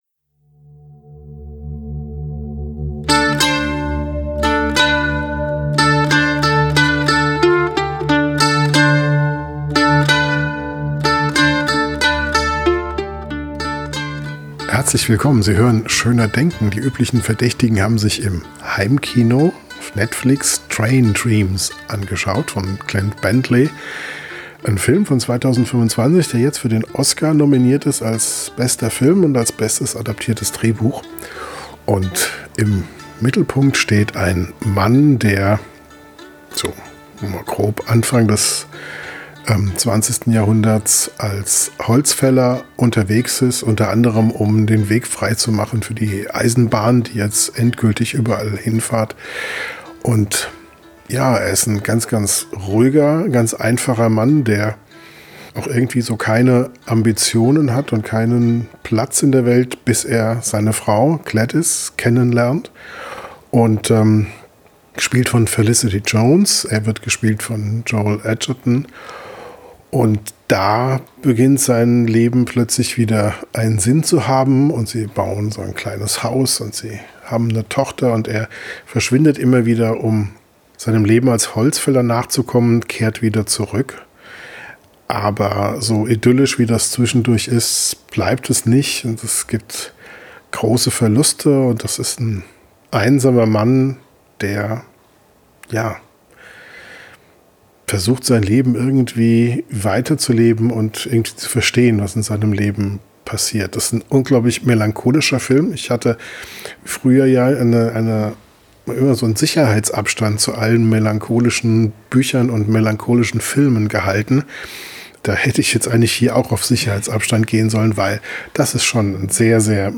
Direkt nach dem Film sprechen wir über Zeitsprünge, Geister und Töchter.